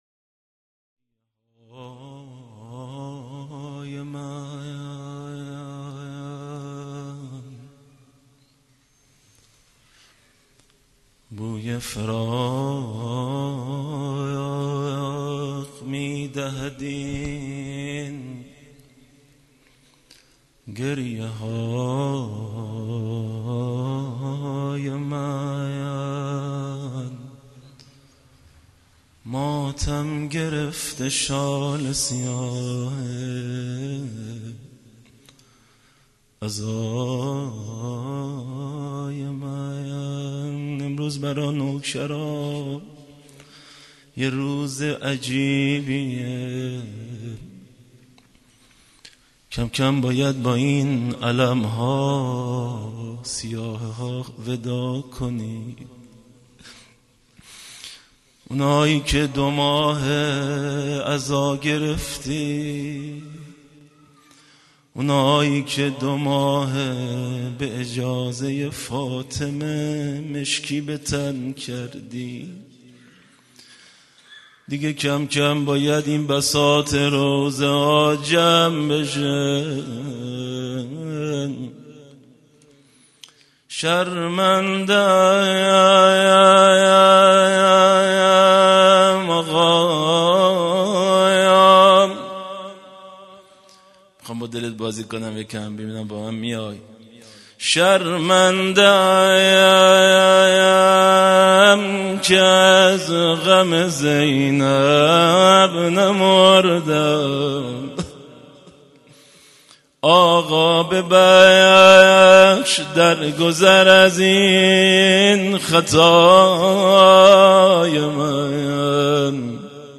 خیمه گاه - هیئت بچه های فاطمه (س) - روضه